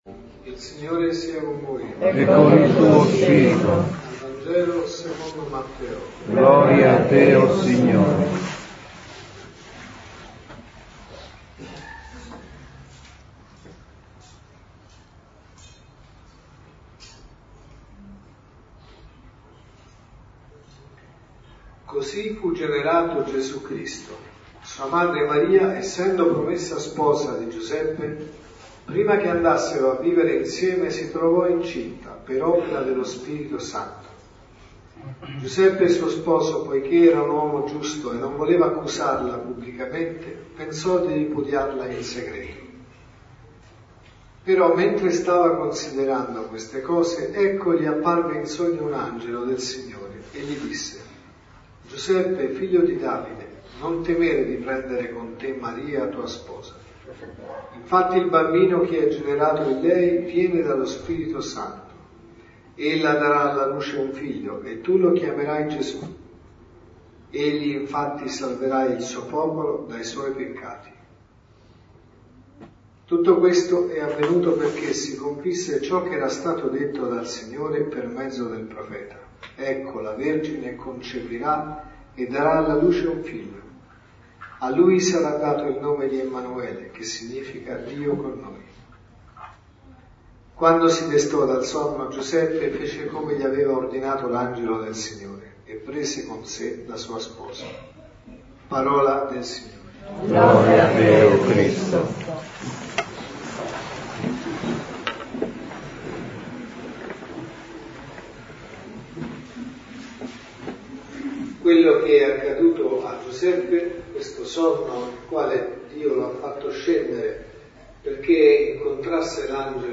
Omelie Messa della mattina